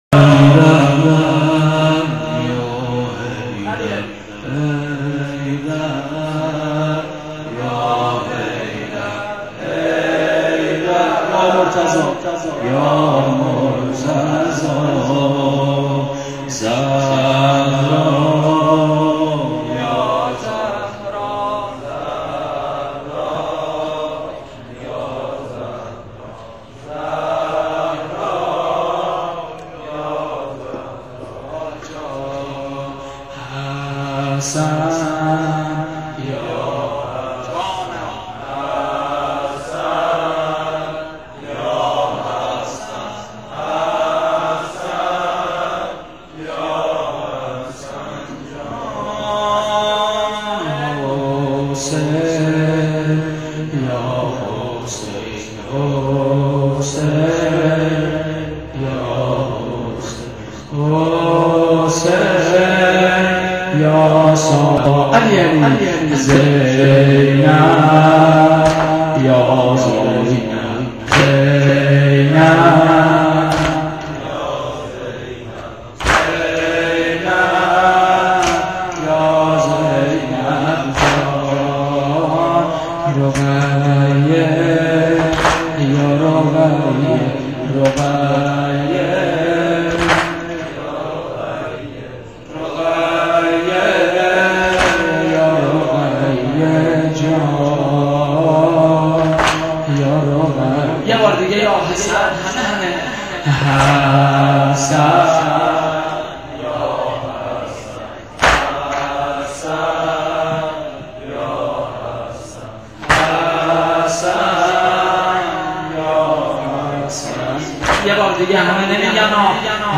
دم-سنگین.wma